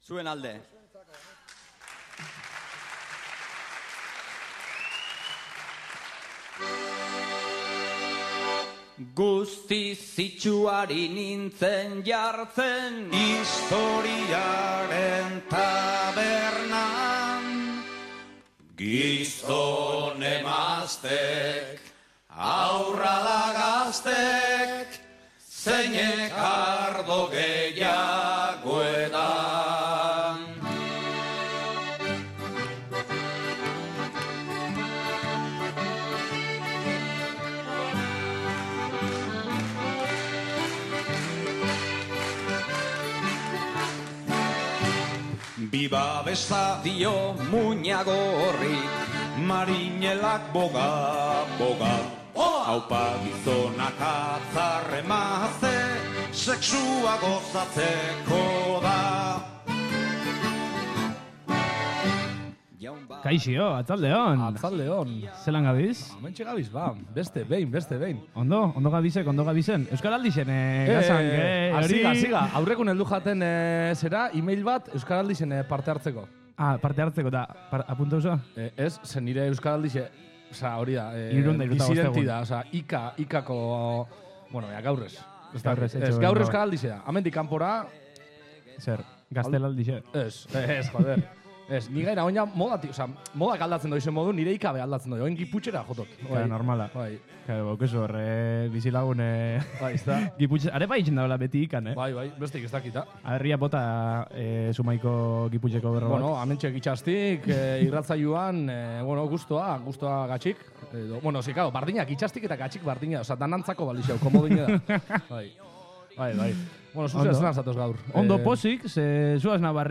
Lagun artien zerbeza batzuk edan eta kontu batzuk, edo tontokerixa batzuk esan; beti be barre artien. Gozamena da irratsaixo bat grabetie taberna baten egotie lez izetie!